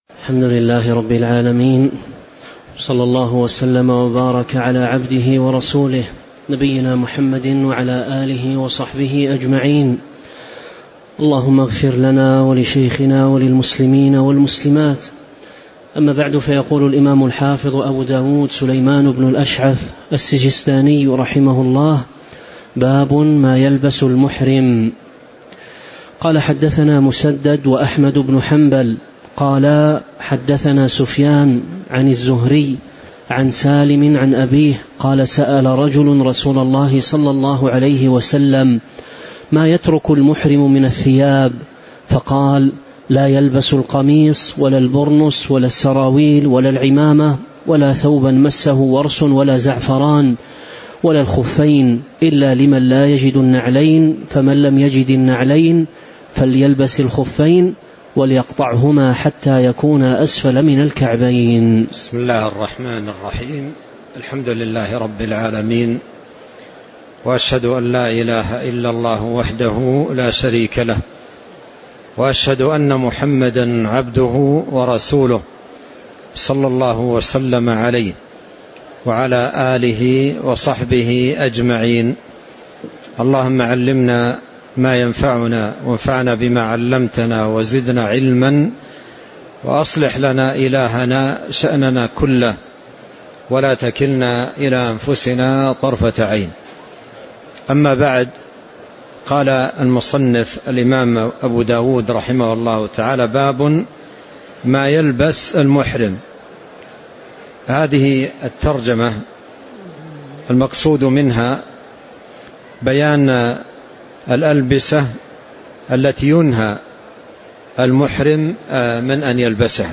تاريخ النشر ٤ ذو الحجة ١٤٤٦ المكان: المسجد النبوي الشيخ